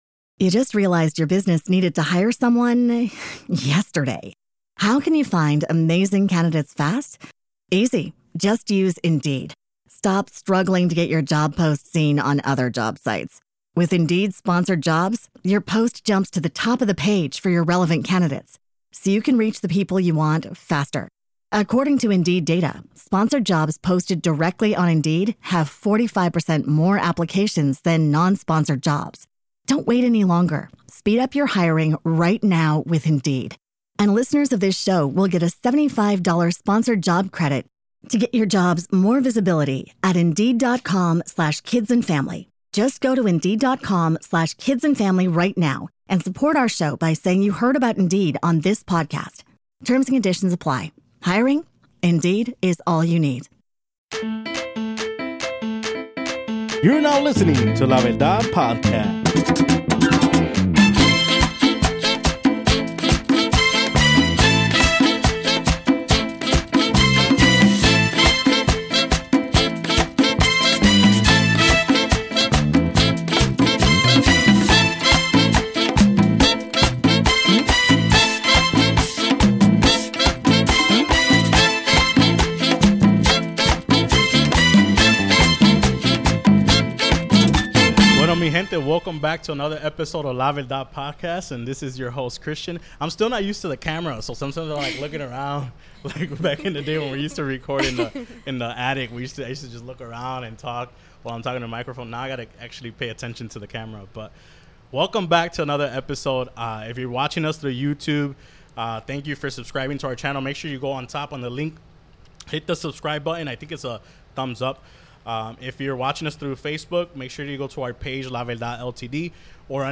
Check out this dope interview for more insights on what has made our guest a top Latina Entrepreneur in the industry.